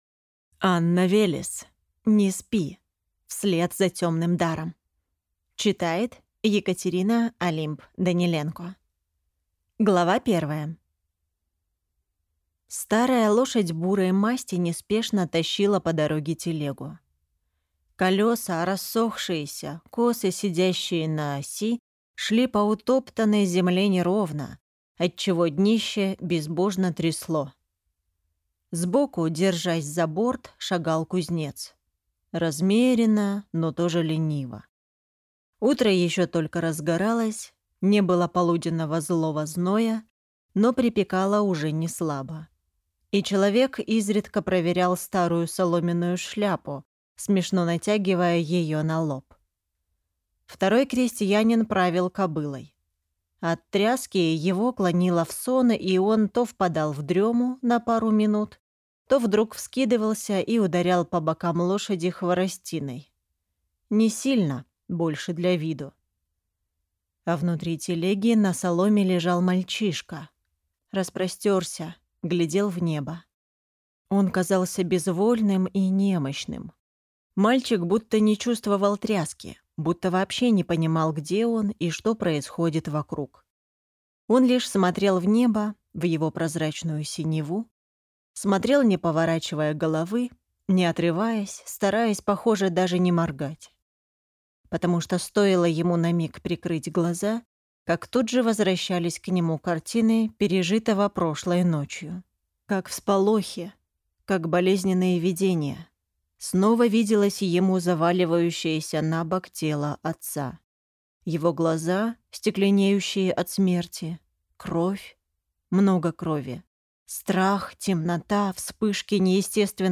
Аудиокнига Не спи! Вслед за темным даром | Библиотека аудиокниг
Прослушать и бесплатно скачать фрагмент аудиокниги